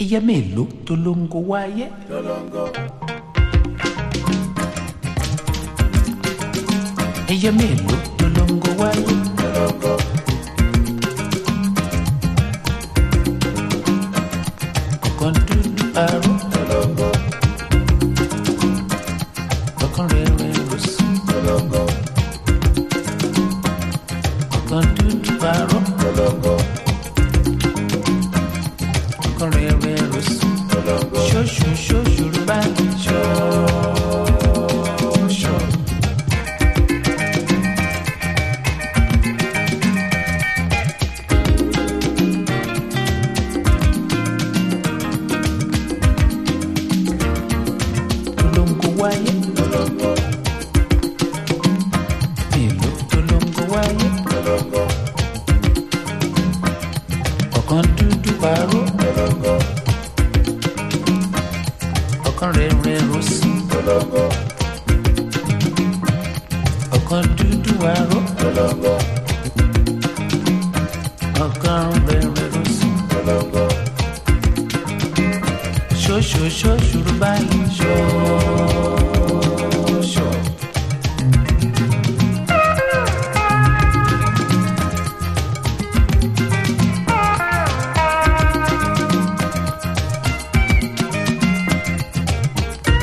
ディープ・ハウス/コズミック・ディスコ好きにも大推薦のミニマル・アフロ/ジュジュ・グルーヴ！